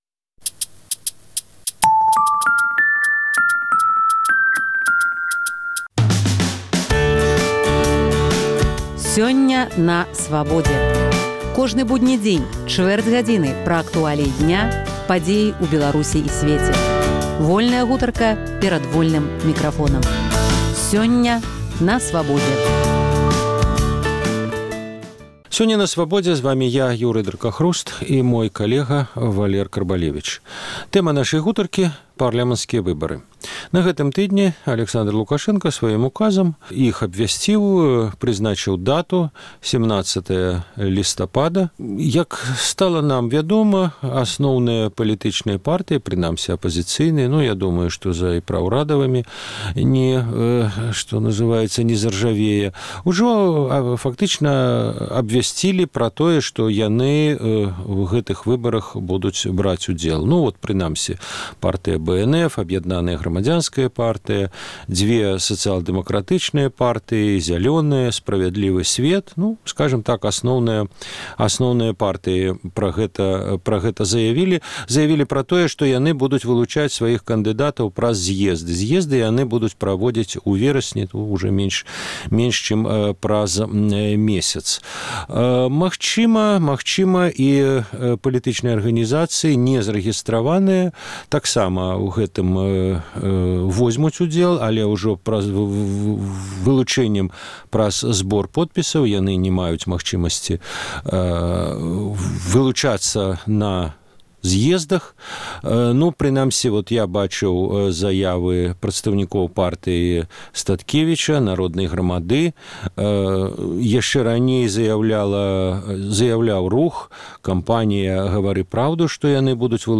Навошта партыі ідуць на выбары? Гутарка